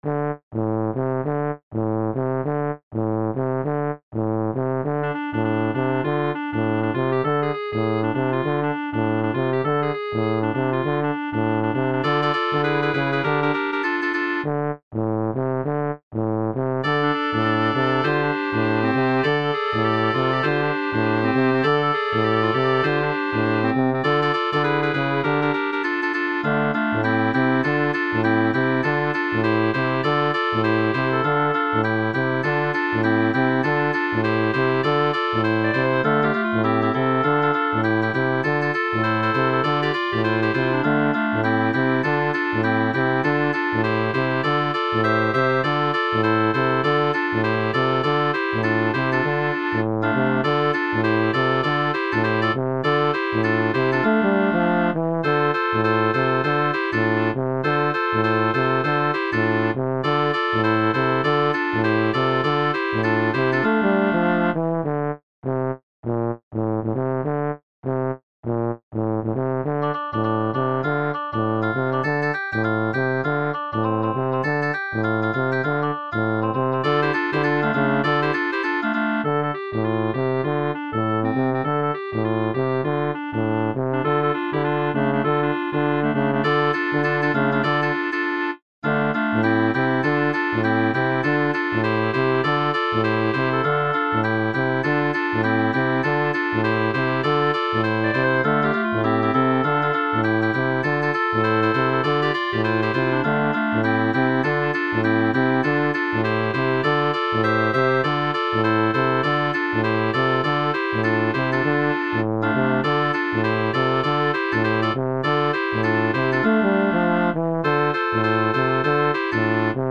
Midi Preview